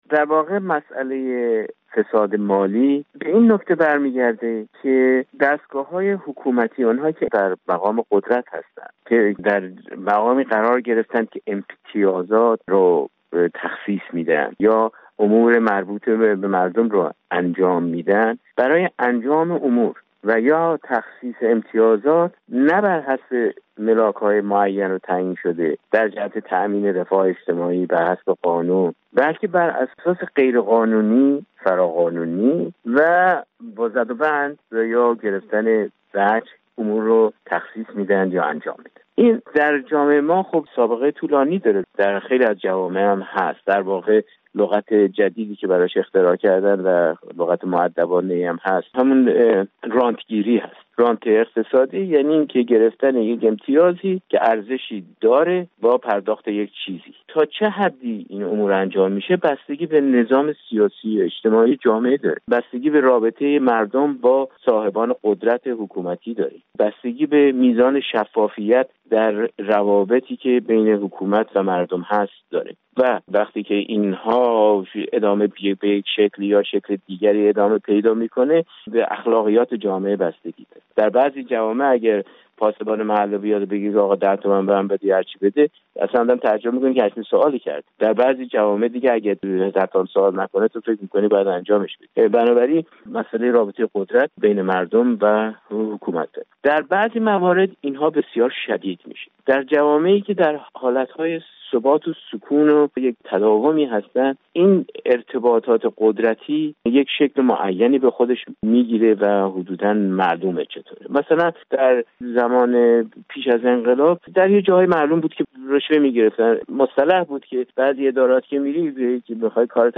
گفتار اقتصادی